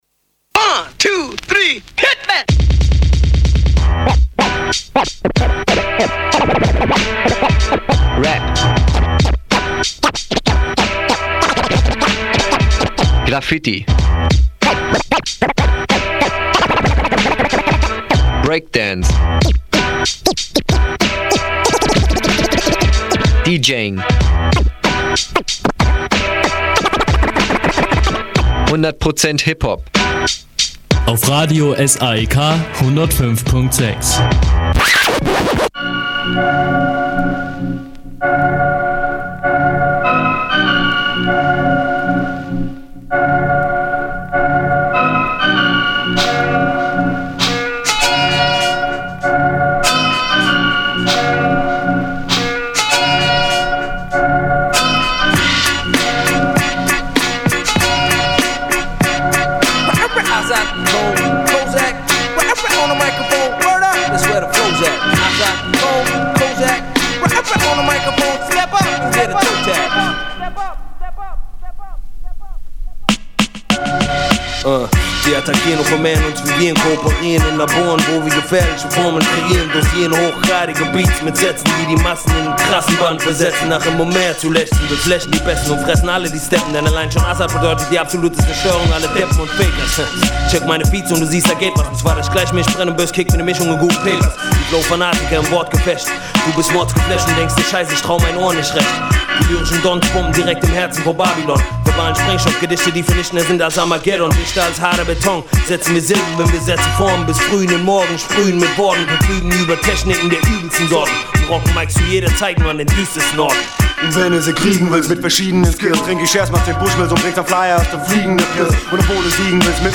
Interview
beim Splash! 2002